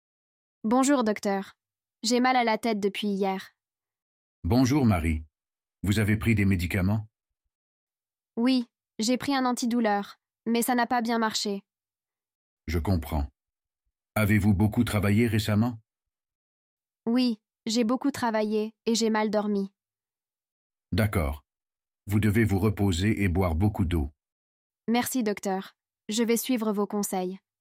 Dialogue en français – Chez le médecin (Niveau A2)